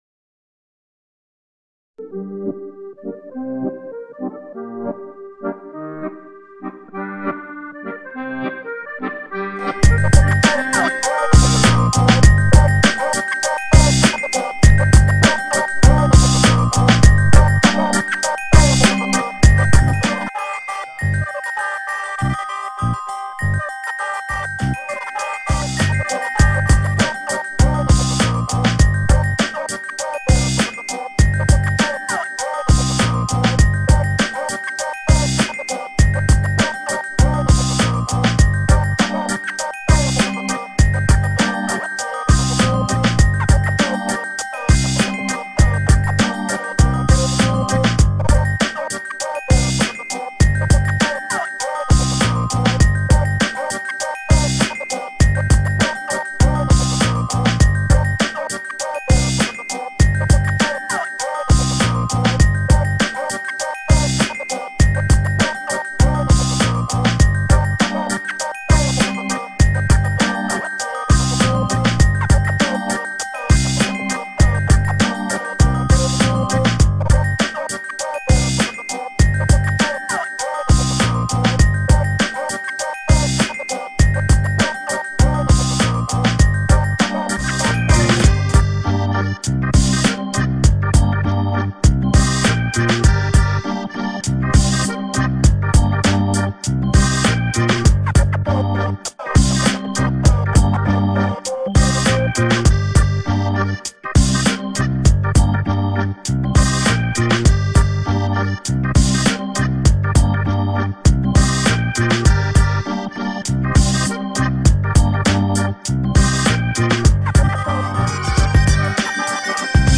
accordeon.mp3